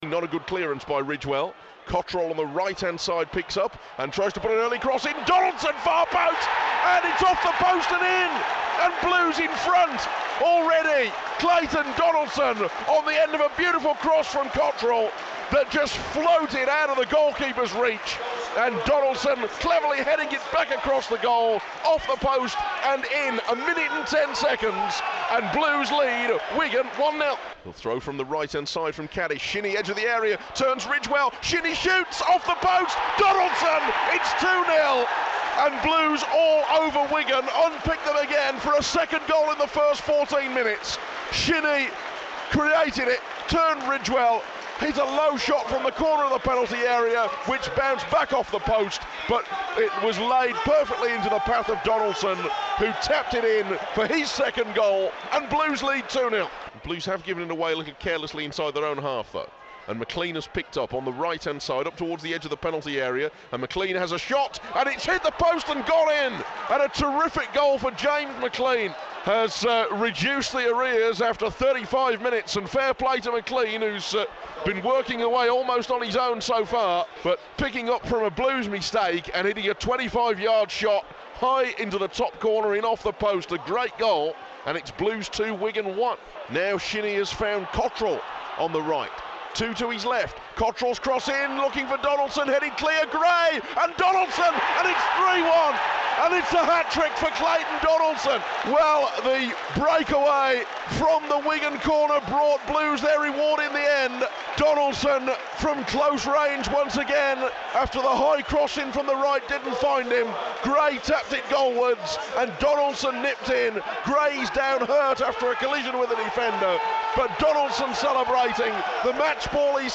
describes the action and talks to Gary Rowett post-match.